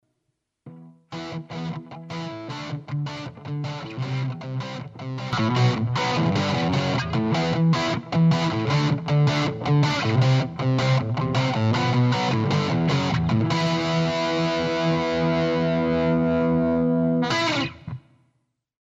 Sulle chitarre distorte diventa semplice evidenziare il corpo a patto di non esagerare nel livello di compressione.
ElectricGtrDist2
Le tracce sono state elaborate inserendo/escludendo il compressore in modo da far apprezzare le differenze e il contributo dell’effetto sul suono.
ElectricGtrDist2.mp3